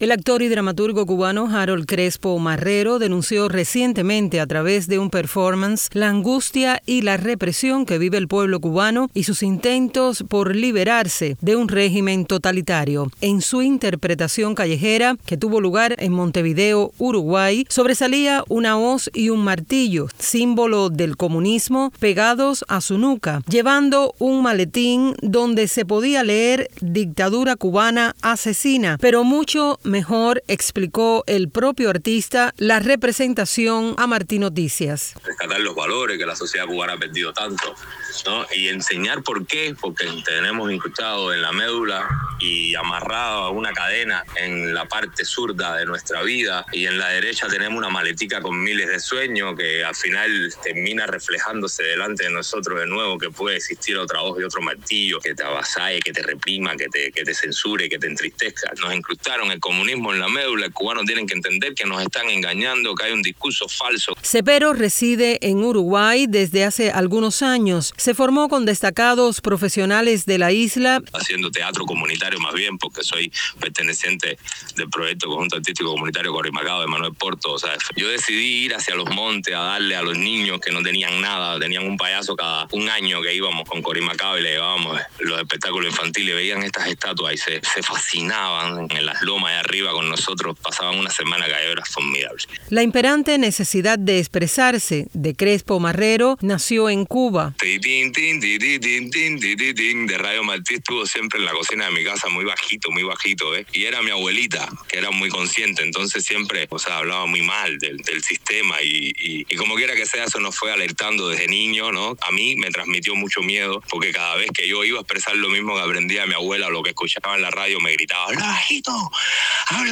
Actor cubano realiza performance por la libertad de Cuba en Montevideo, Uruguay